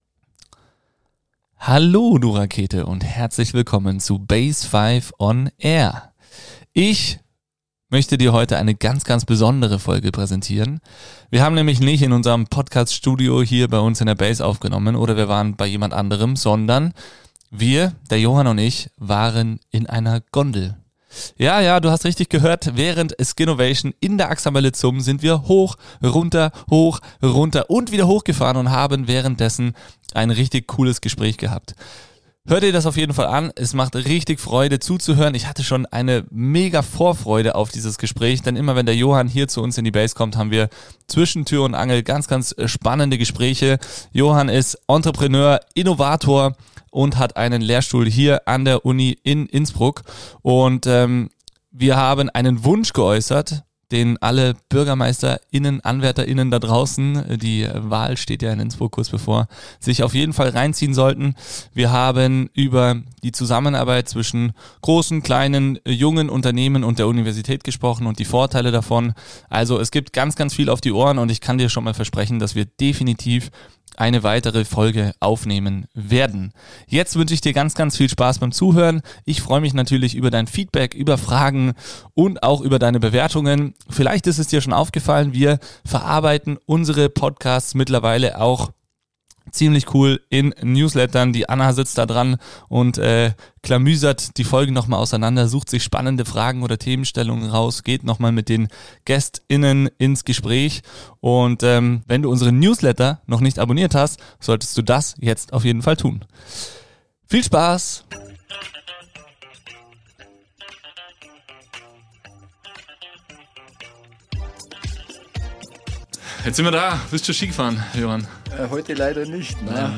Im Rahmen der größten StartUp Konferenz in Tirol geht es um die Vorteile Innsbrucks für junge Firmen, wirtschaftliche Möglichkeiten und die Zusammenarbeit zwischen Universität und Privatwirtschaft. Es werden Wünsche geäußert und wertvolle Tipps rund ums Thema gründen und führen gegeben.